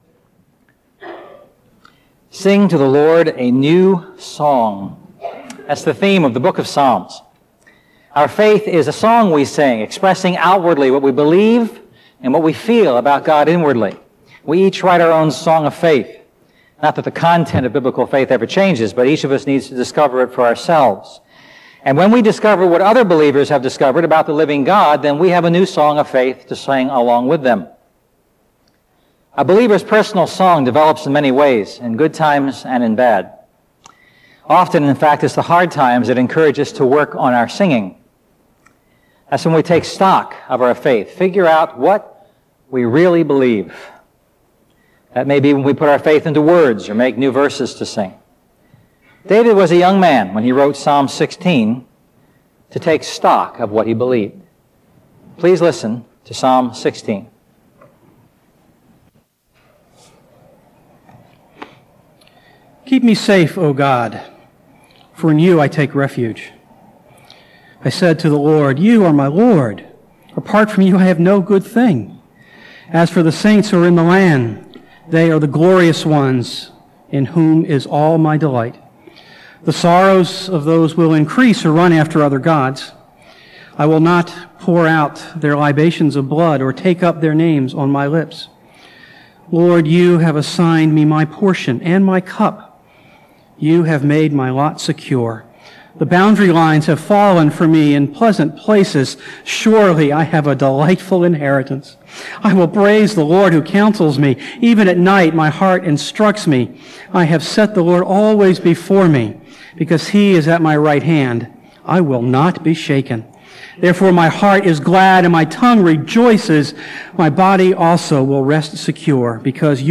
A message from the series "A New Song."